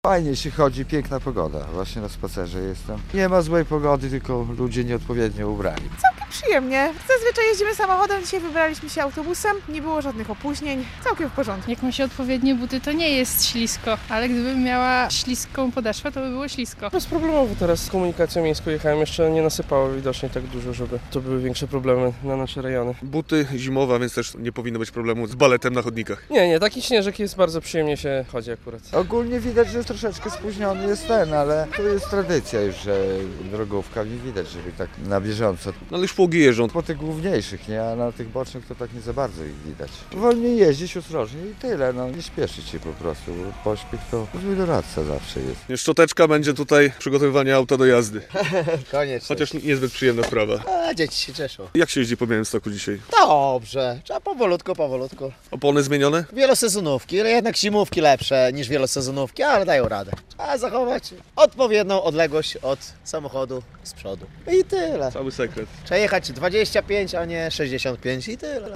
Trudne warunki na drogach - relacja